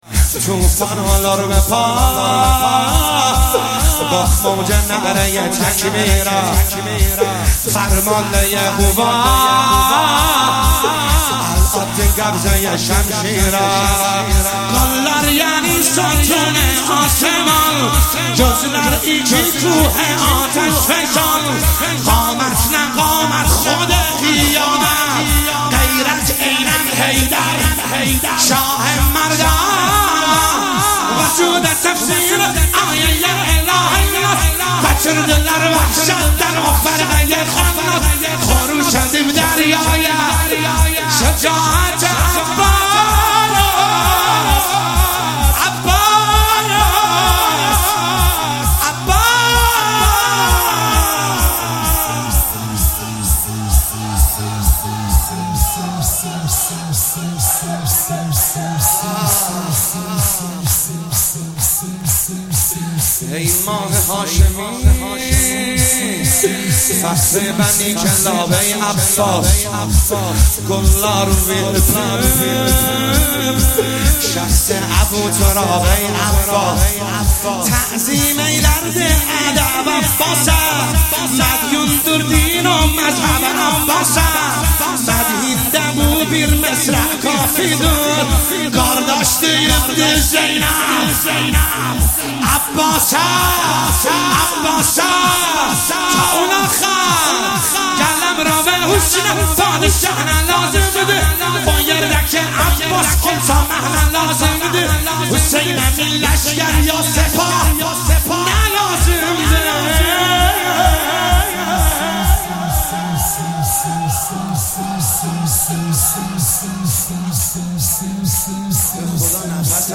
شب تاسوعا محرم1401 - شور ترکی